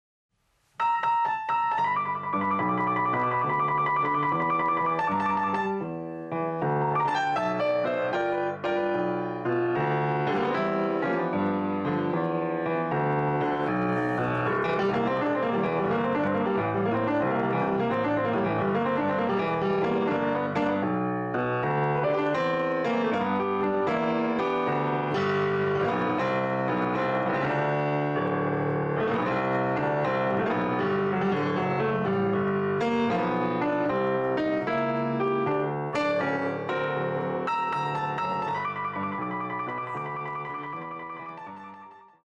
Style: Blues Piano